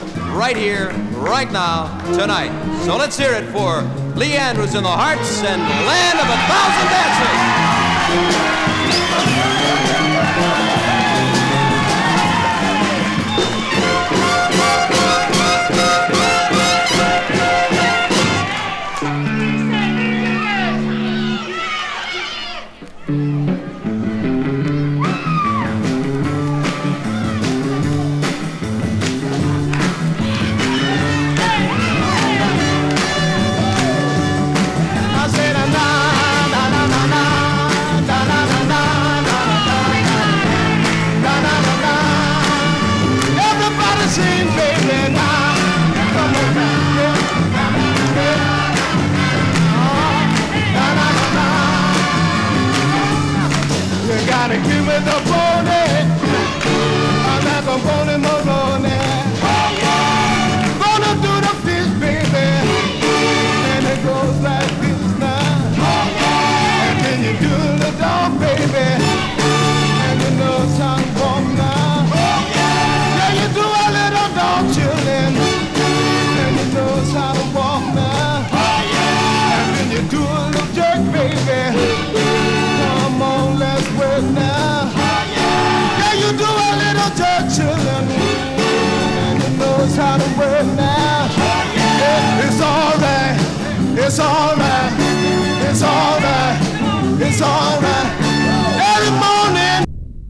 熱狂の坩堝という観客の盛り上がりを封じ込めたライヴ・アルバムです！＊音の薄い部分でチリチリ・ノイズ。時折パチ・ノイズ。